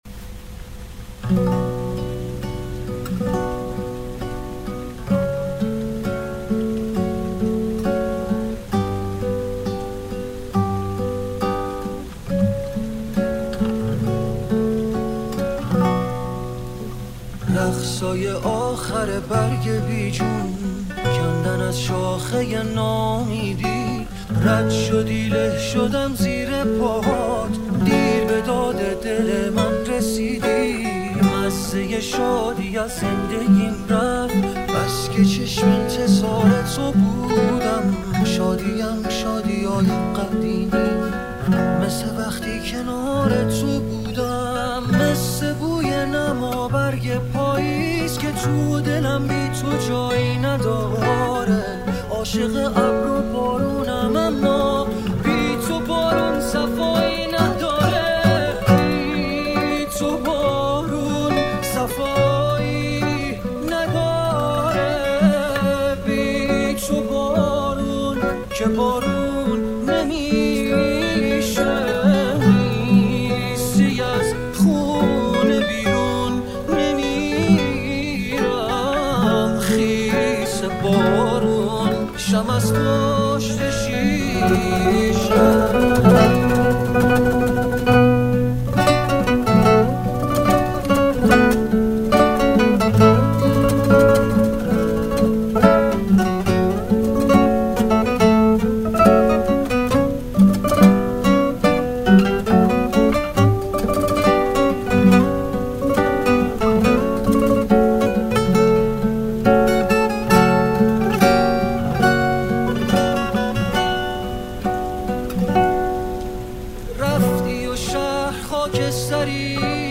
Guitar Version